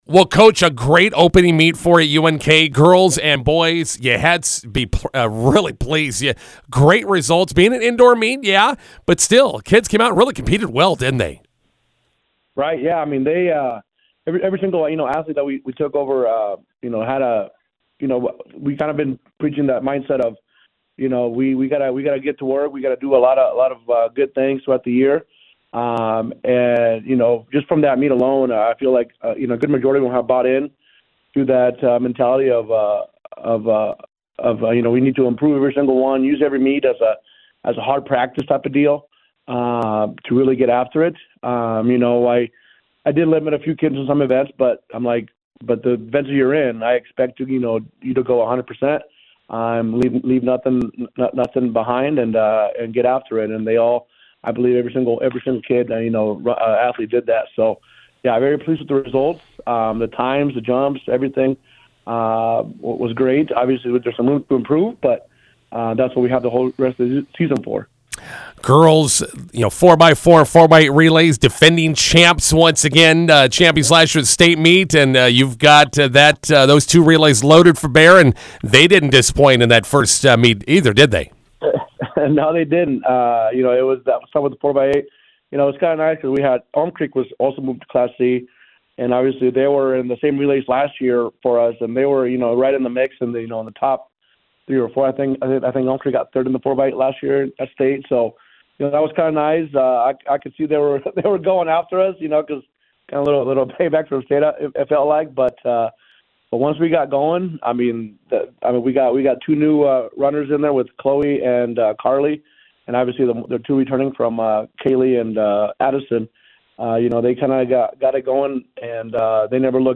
INTERVIEW: Maywood-Hayes Center Track and Field with good opening marks/times at UNK Indoor.